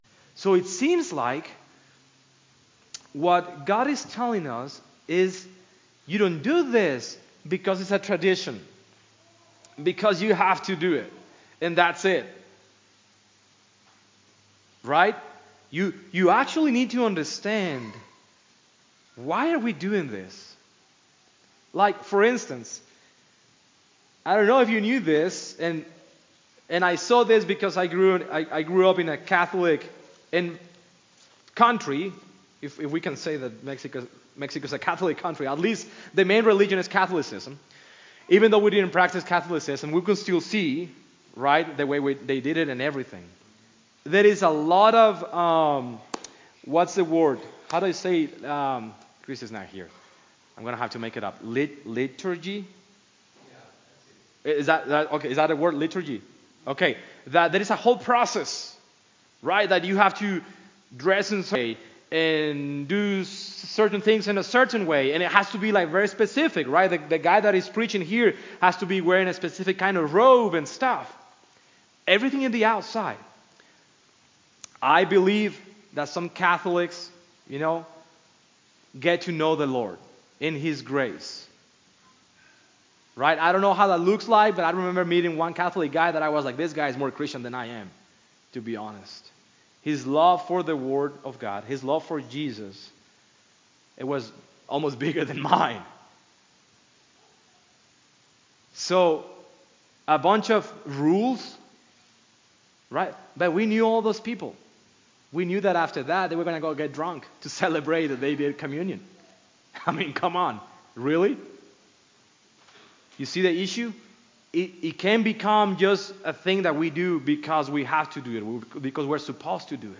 Live Broadcast-Nov 3 2024